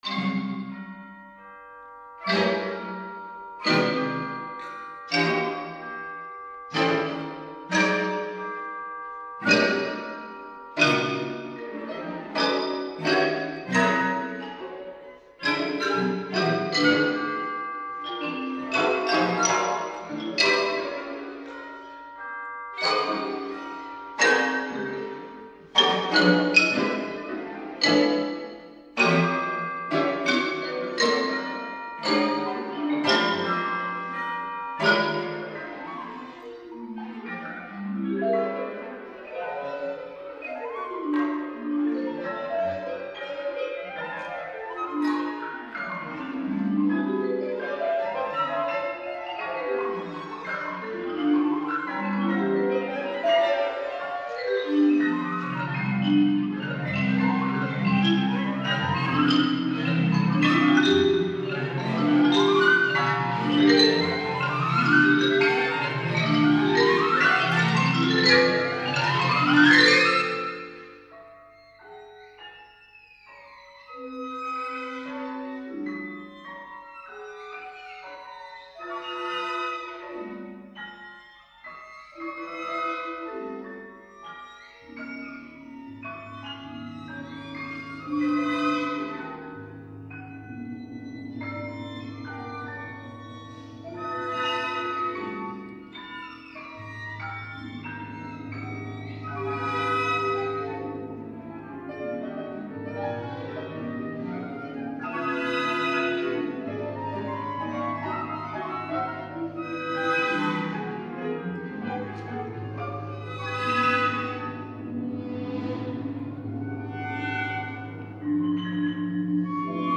O Llaqui para flauta, clarinete, violín, cello y percusión